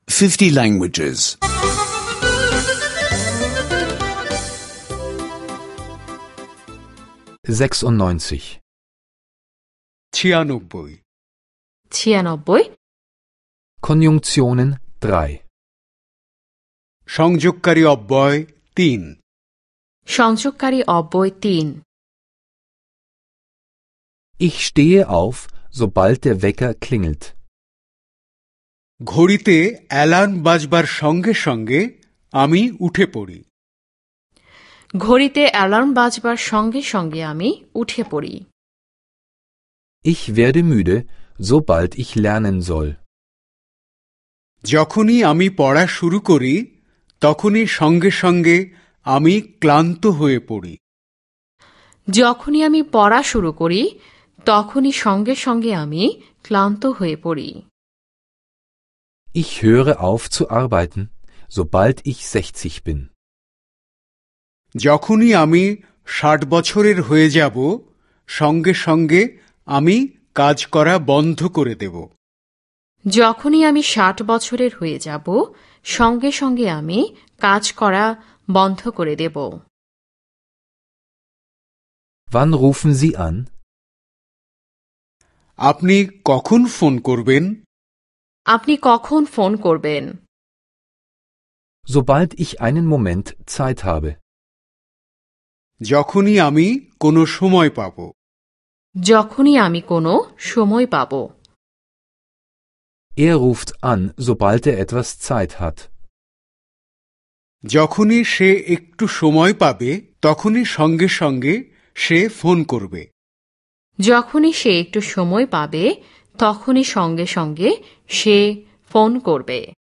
Bengali Sprache-Audiokurs (online anhören)